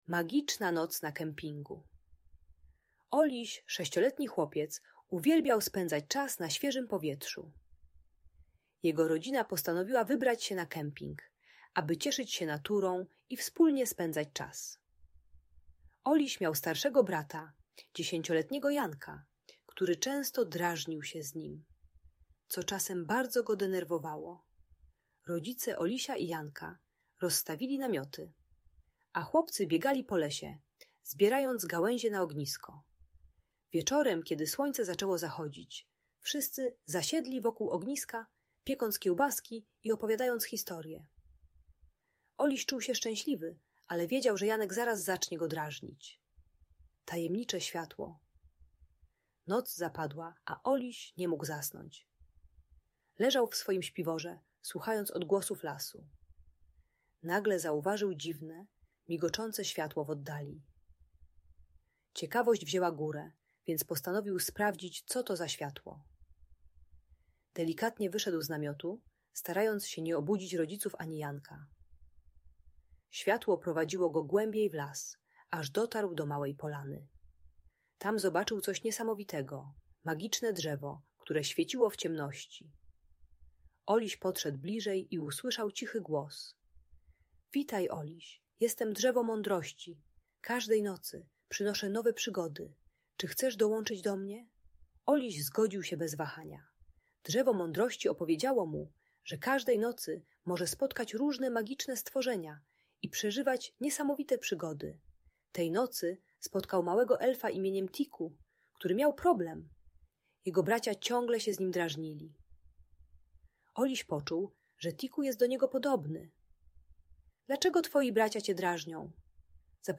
Magiczna Noc na Kempingu - Audiobajka